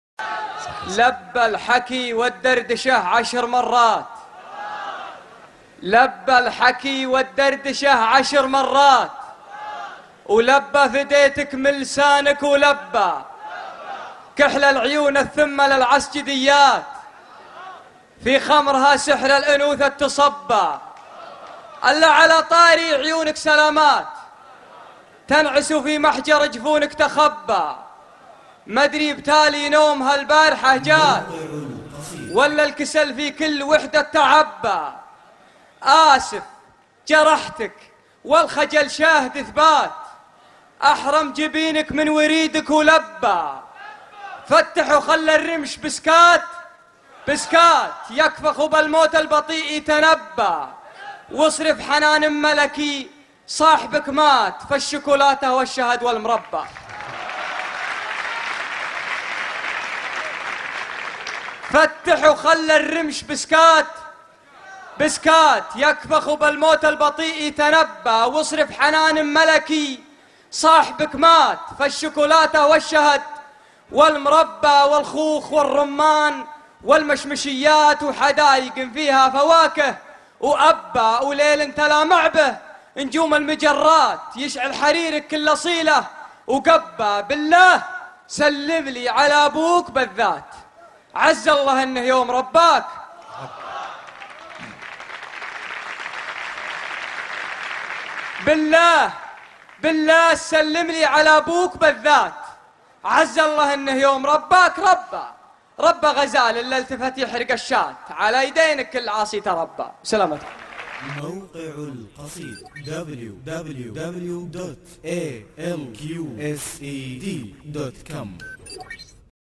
لبى الحكي - امسية ليالي فبراير 2012